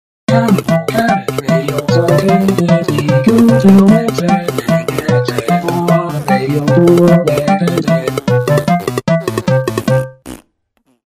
JINGLES